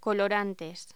Locución: Colorantes
voz